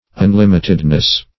Un*lim"it*ed*ness, n.